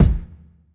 Medicated Kick 18.wav